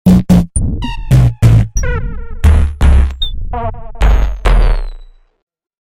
Звуки разряженной батареи